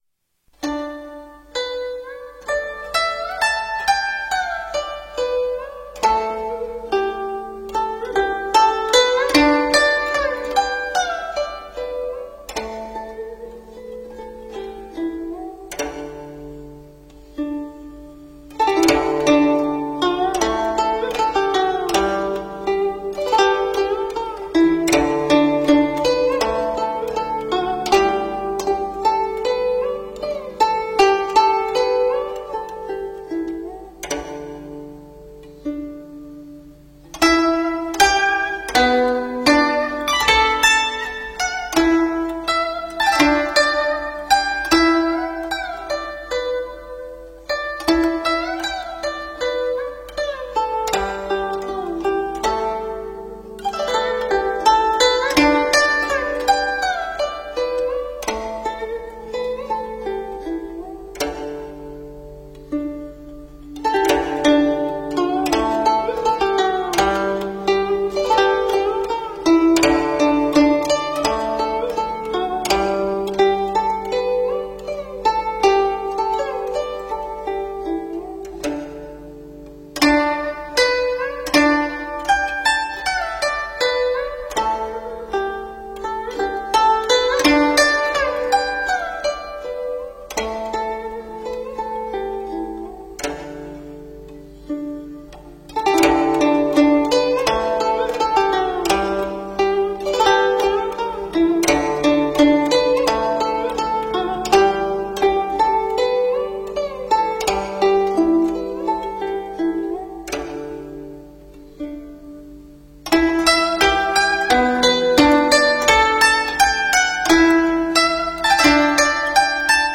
纯音乐-西厢词--古筝独奏
纯音乐-西厢词--古筝独奏 冥想 纯音乐-西厢词--古筝独奏 点我： 标签: 佛音 冥想 佛教音乐 返回列表 上一篇： 观想莲华--福音佛乐团 下一篇： 广陵散--古琴 相关文章 南无大智文殊师利菩萨赞偈--佚名 南无大智文殊师利菩萨赞偈--佚名...